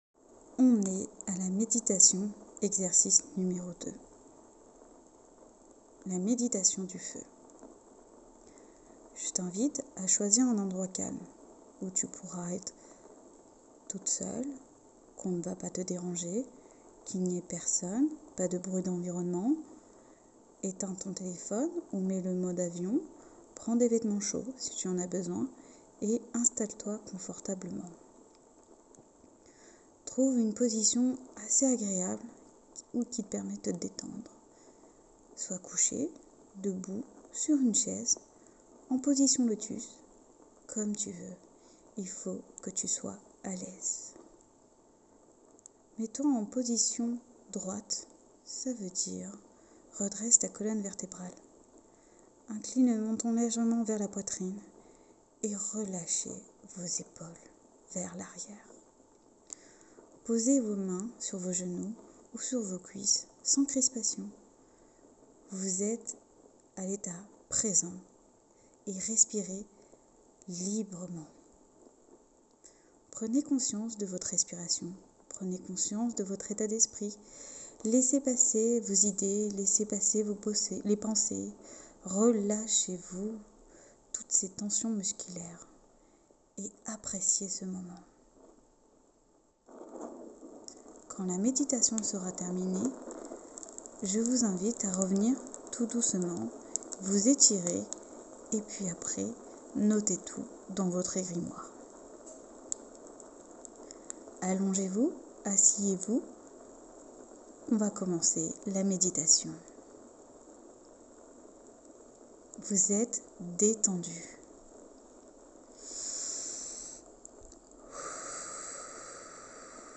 Exercice2: Méditation